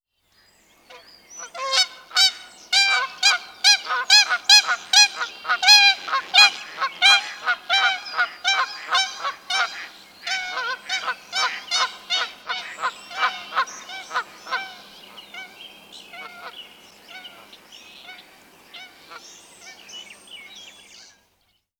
Kanadagans Ruf
• Ihr tiefer trompetenartiger Ruf ist oft schon aus weiter Entfernung hörbar.
Kanadagans-Ruf-Voegle-in-Europa.wav